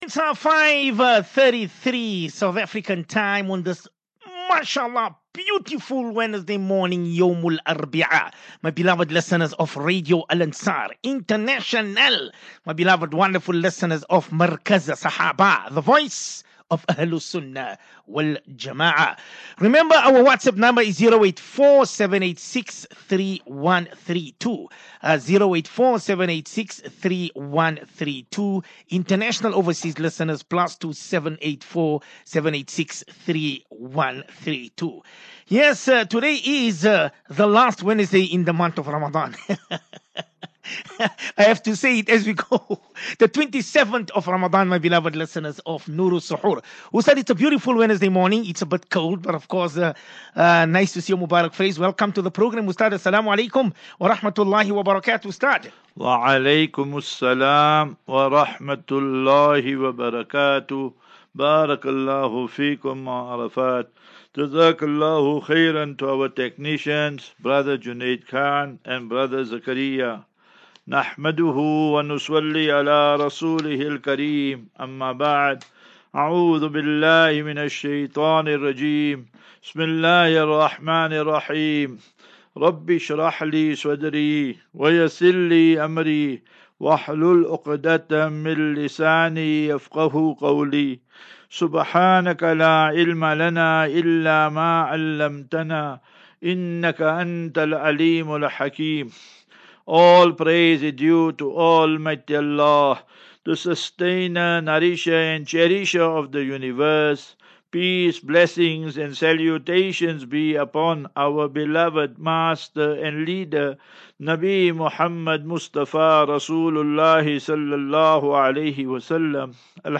Daily Naseehah.